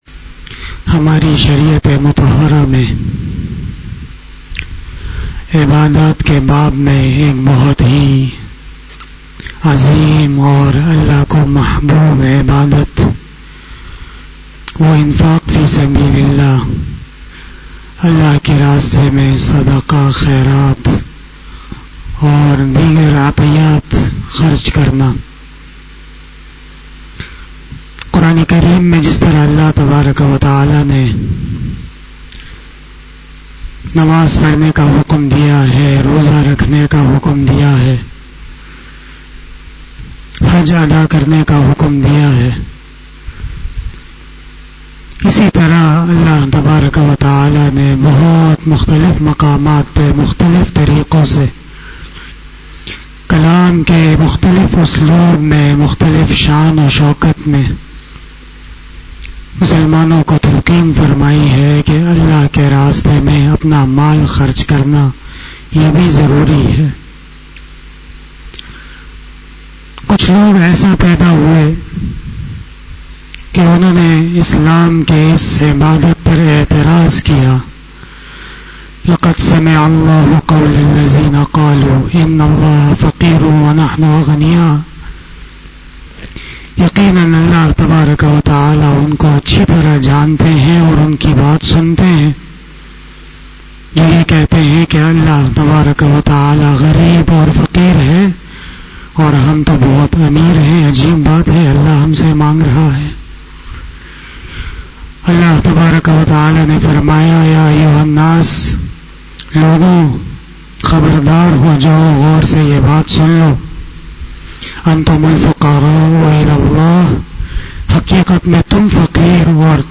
After Fajar Namaz Bayan